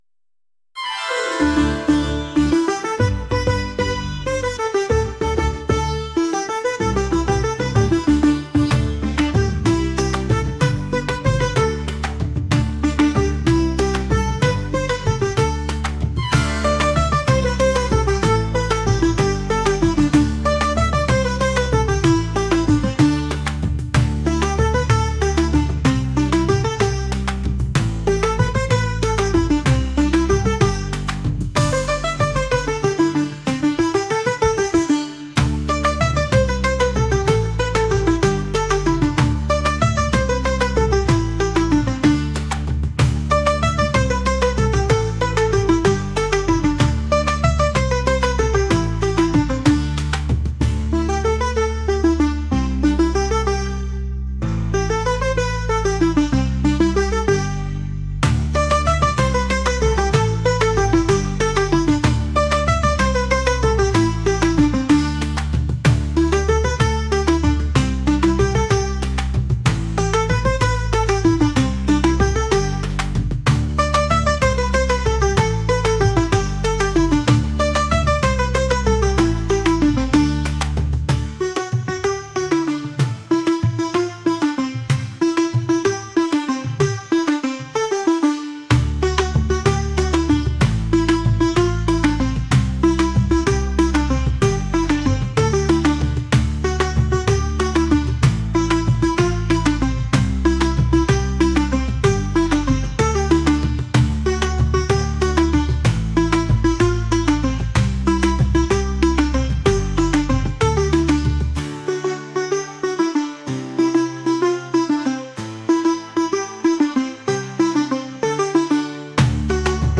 traditional | classical | energetic